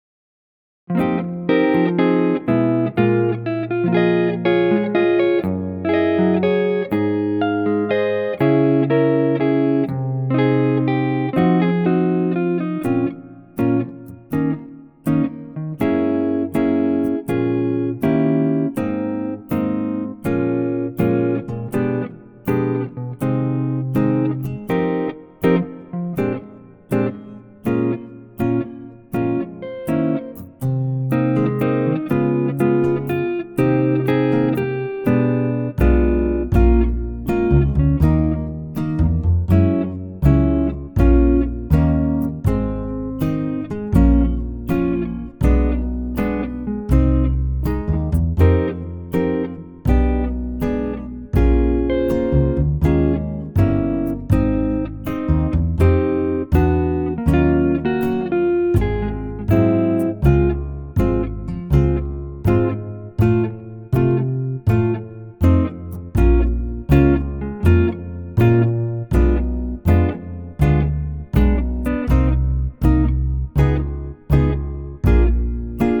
PIANO REMOVED!
key - Ab - vocal range - Ab to Bb